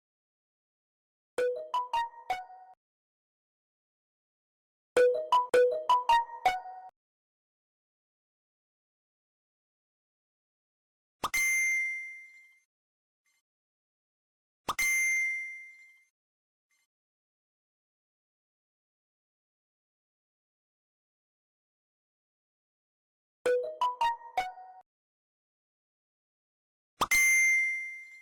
ambiance.mp3